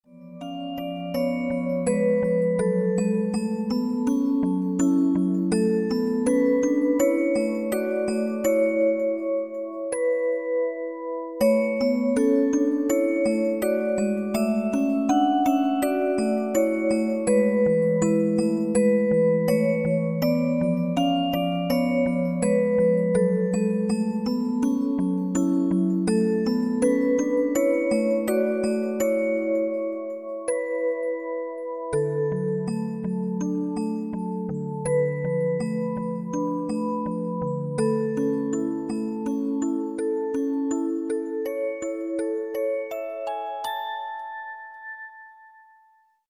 инструментальные
OST
простые